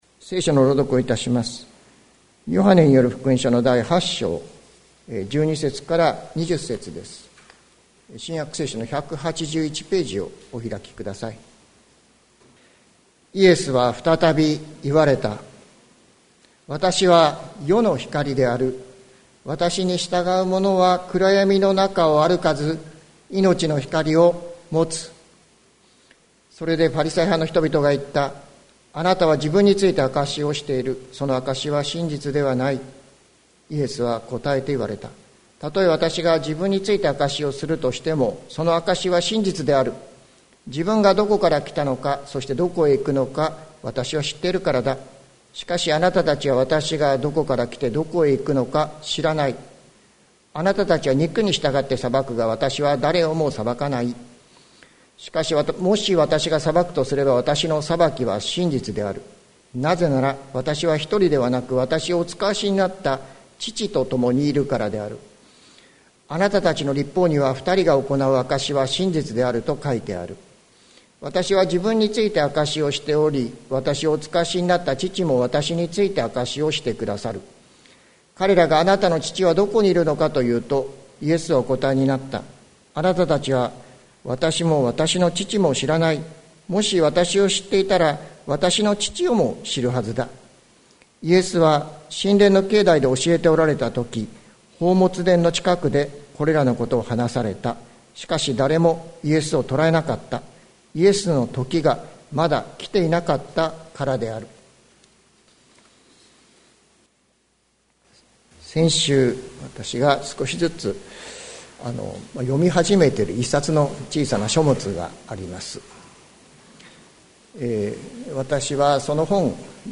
2022年07月31日朝の礼拝「世の光、イエス・キリスト」関キリスト教会
説教アーカイブ。